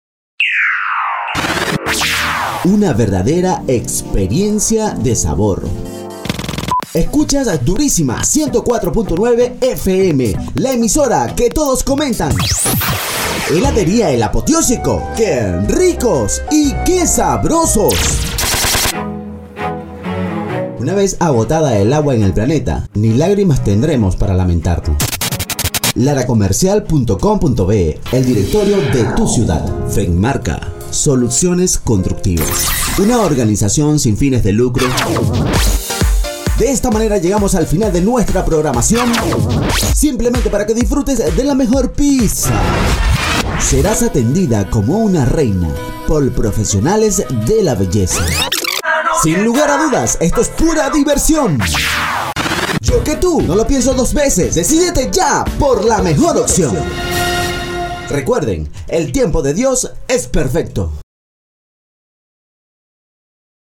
Locutor Profesional Certificado, Voice Over, Acento Neutro
kastilisch
Sprechprobe: Werbung (Muttersprache):